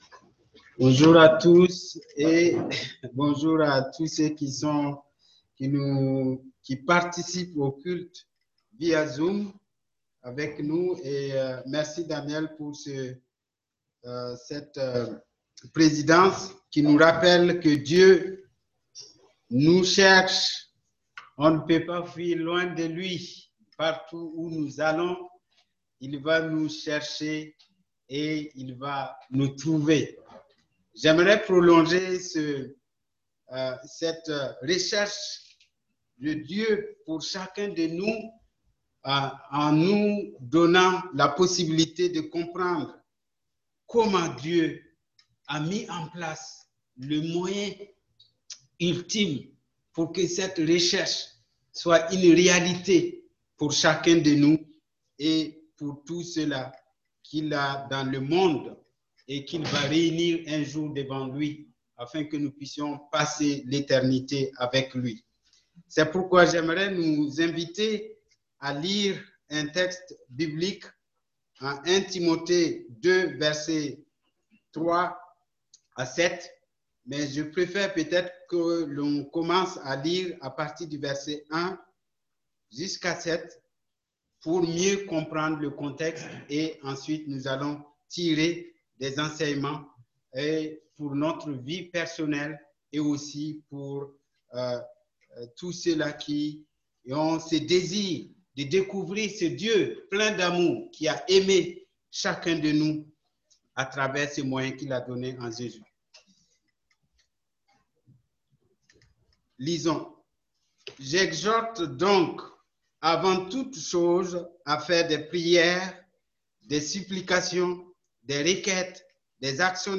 Type De Service: Messages du dimanche